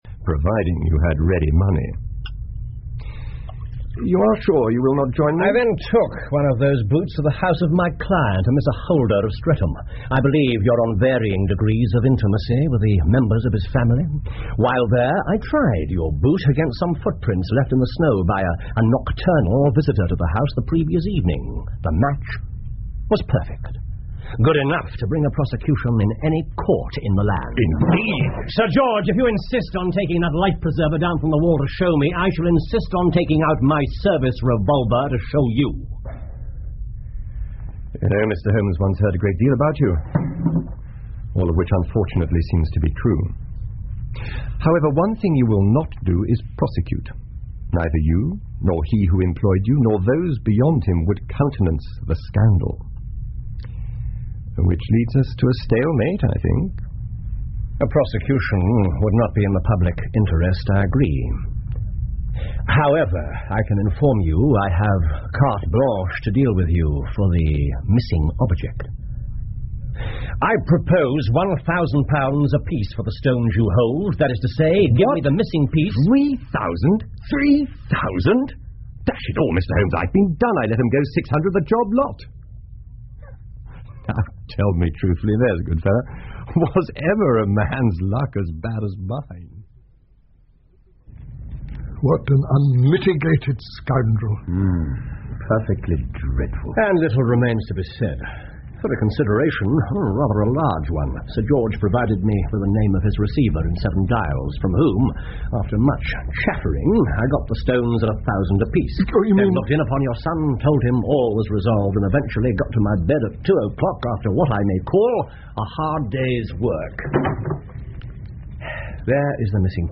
福尔摩斯广播剧 The Beryl Coronet 9 听力文件下载—在线英语听力室
在线英语听力室福尔摩斯广播剧 The Beryl Coronet 9的听力文件下载,英语有声读物,英文广播剧-在线英语听力室